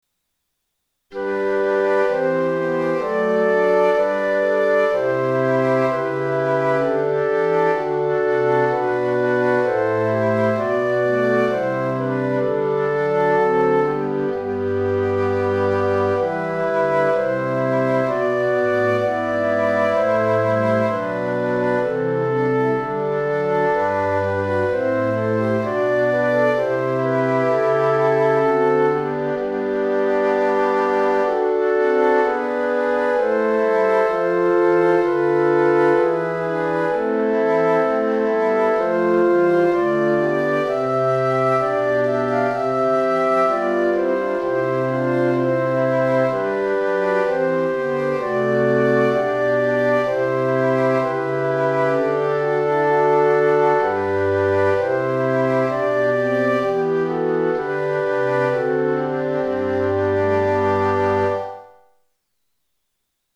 Sample Sound for Practice 練習用参考音源：MIDI⇒MP3　Version A.17
Tonality：G (♯)　Tempo：Quarter note = 64
1　 Recorder